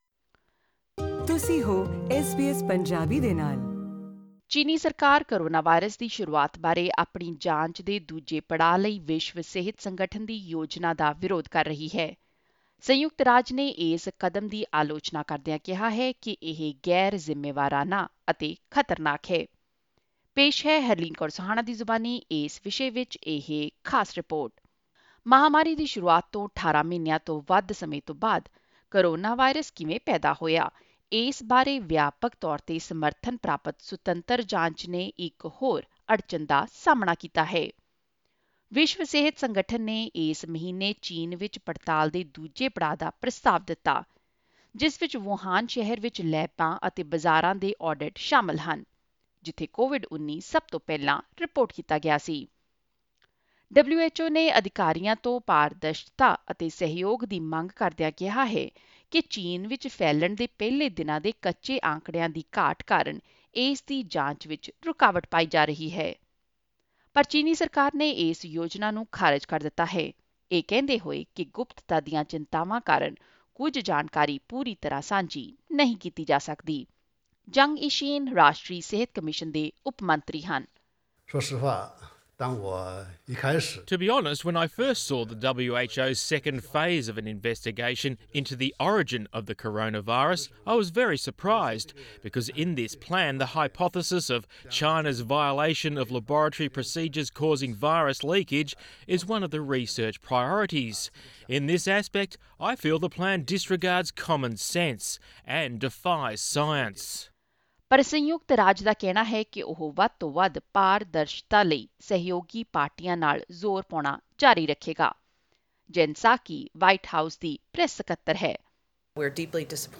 Zeng Yixin is vice-minister of the National Health Commission.